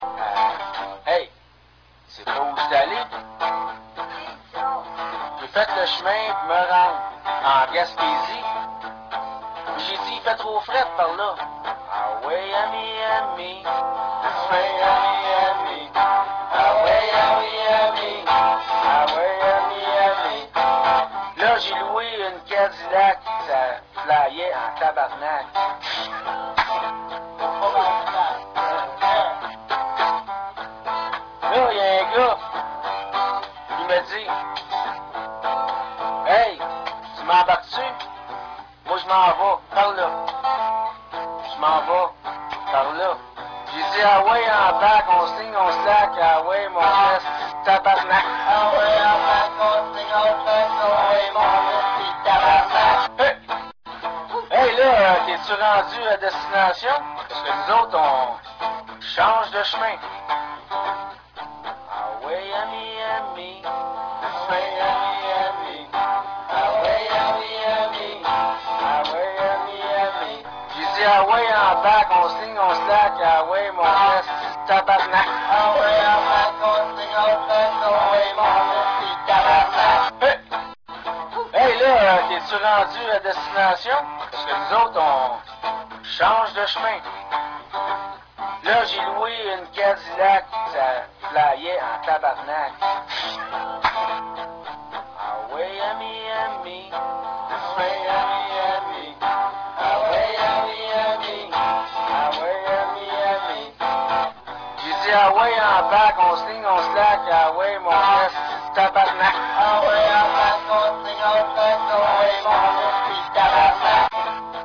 VOICI UN PEU DE CHANSON QUE JE FAIS DANS LES BARS AU QUEBEC